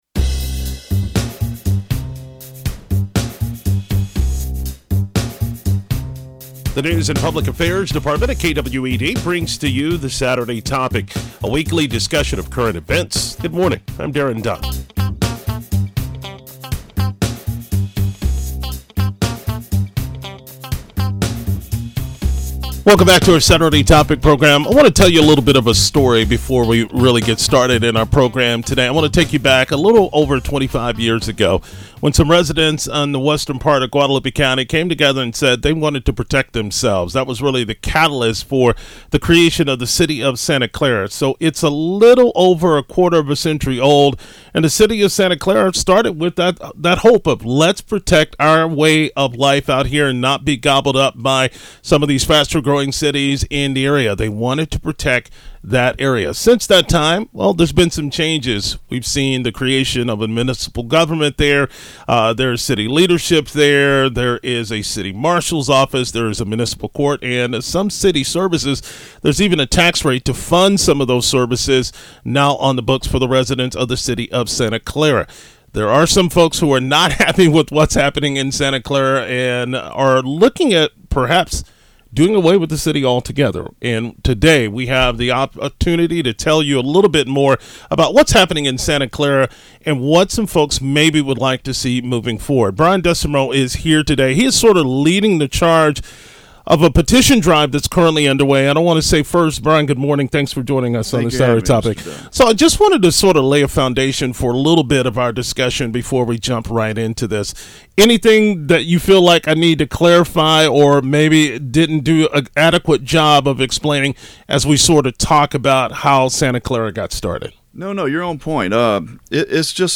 LISTEN NOW: KWED’s interview on the possible disincorporation of the city of Santa Clara